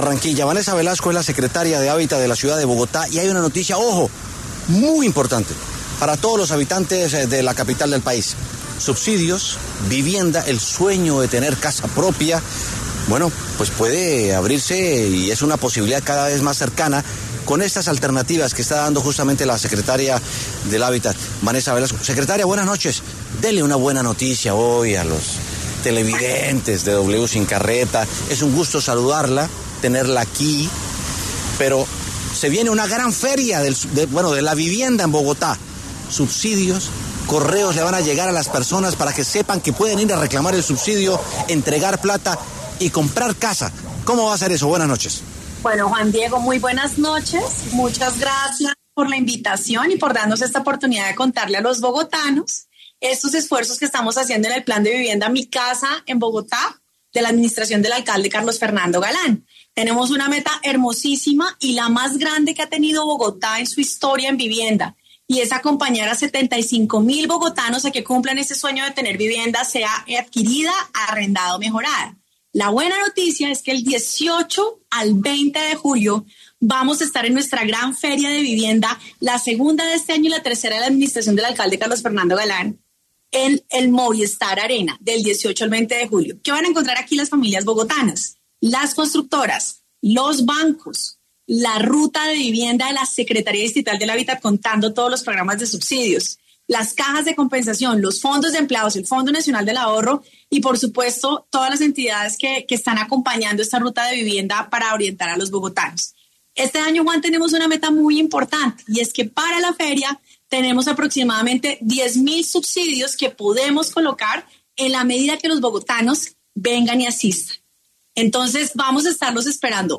Vanessa Velasco, secretaria de Hábitat de Bogotá, pasó por los micrófonos de W Sin Carreta y habló sobre las nuevas alternativas que tiene la entidad para todos los ciudadanos que estén en busca de vivienda propia.